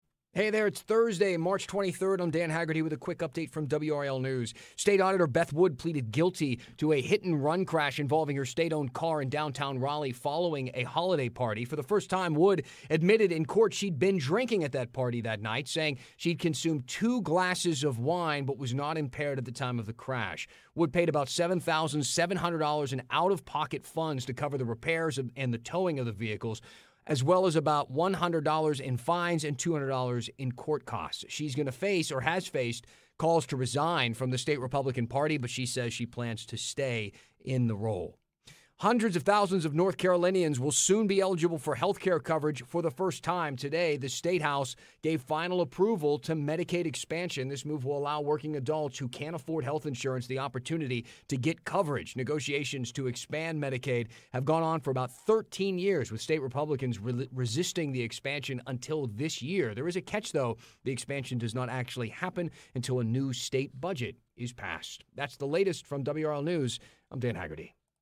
WRAL Newscasts